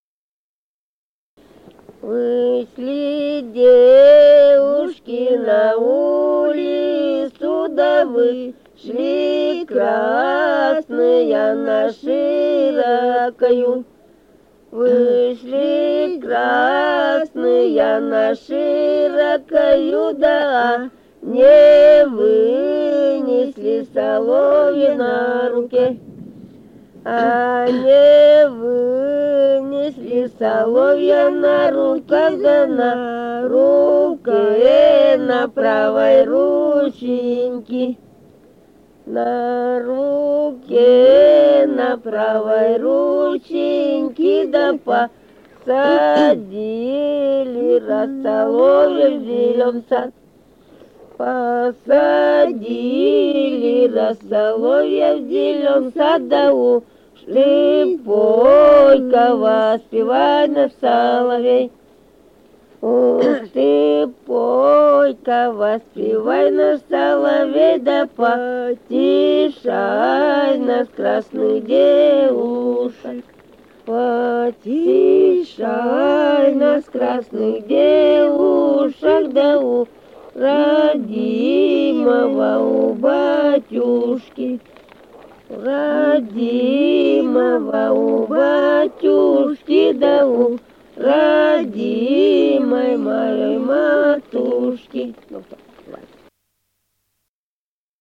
| filedescription = «Вышли девушки на улицу», круговая.
Республика Алтай, Усть-Коксинский район, с. Мульта, июнь 1980.